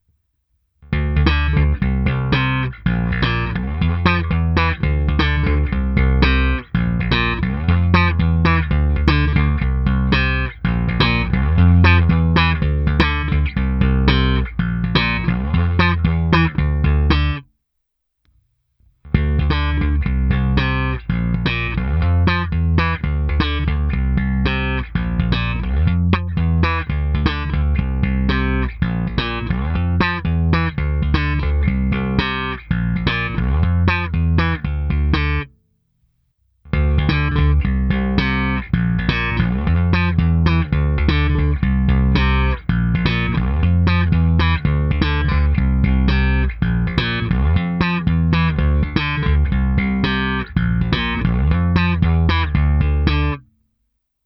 V následující nahrávce s baskytarou Fender American Professional II Precision Bass V jsou tři části. Hra bez kompresoru, hra s kompresorem Ampeg Opto Comp a v třetí části pro srovnání hra s kompresorem TC Electronic SpectraComp. To samé v ukázce slapem.
Ukázka slapu